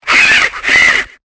Cri de Vaututrice dans Pokémon Épée et Bouclier.